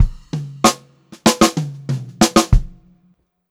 96PO2FILL1-L.wav